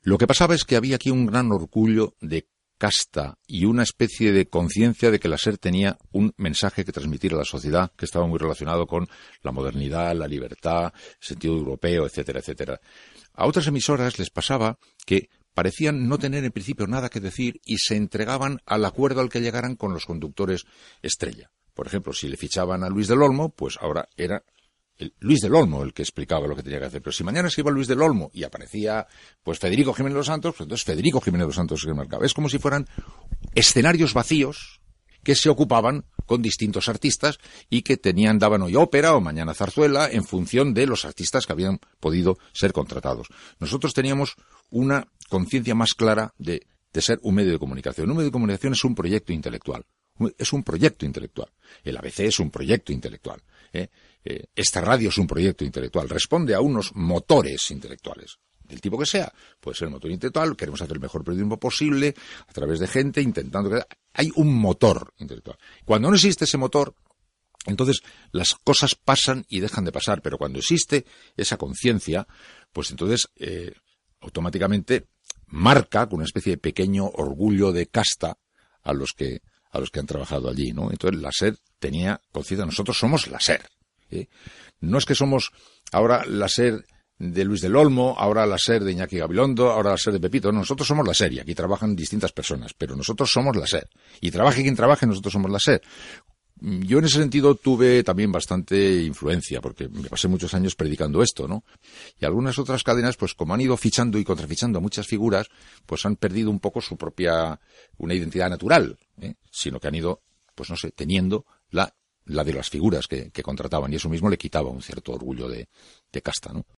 Resposta d'Iñaki Gabilondo en una entrevista explicant una característica de la Cadena SER